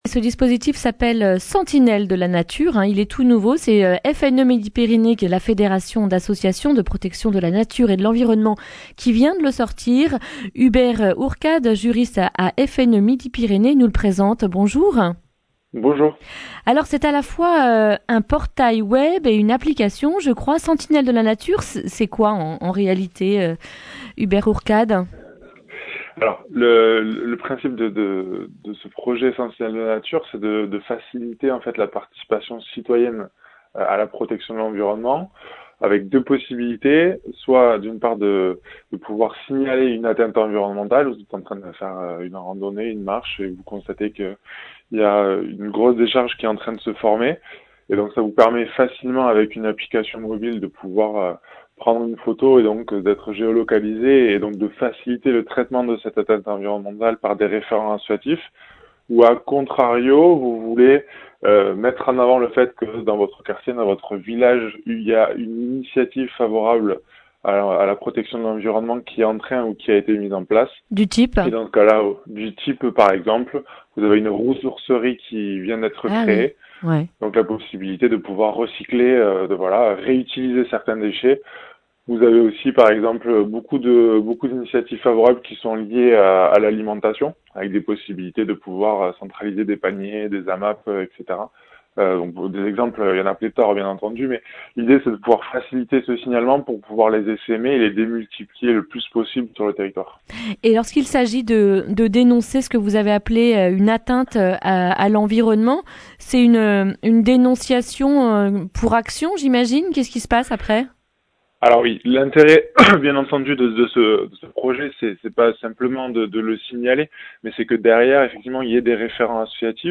mardi 8 octobre 2019 Le grand entretien Durée 10 min